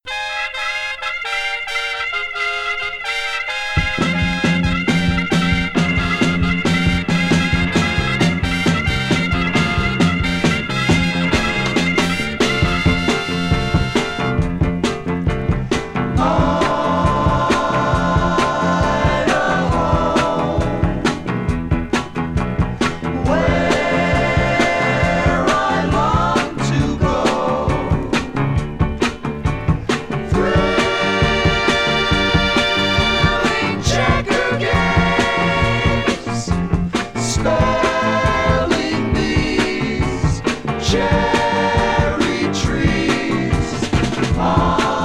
Rock, Pop, Psychedelic Rock　USA　12inchレコード　33rpm　Stereo